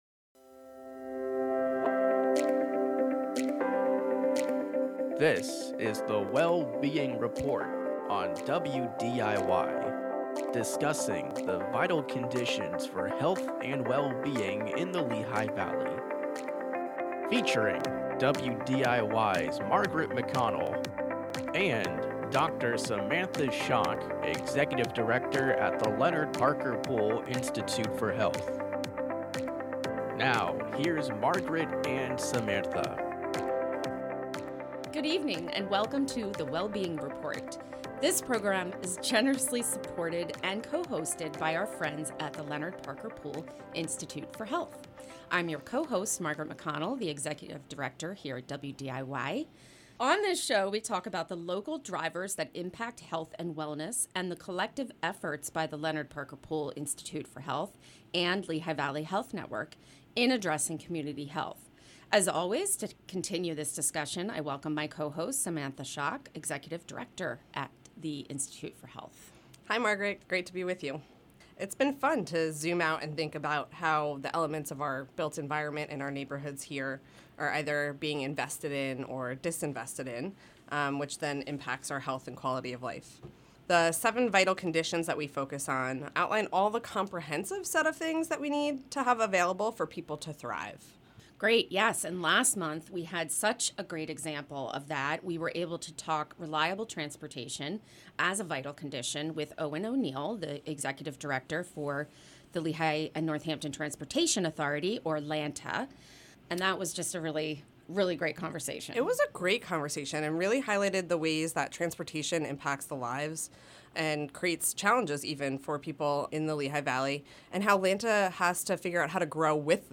host monthly roundtable discussions on the social determinants of health and the impact on communities